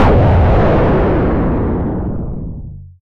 ahh4.ogg